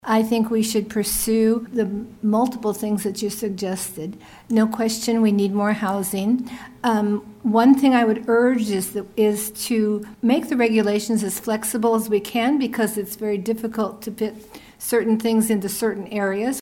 Commissioner Karen McCulloh made housing a major point of her campaign and voiced her support for more housing while calling for the loosening of regulations to allow for more building.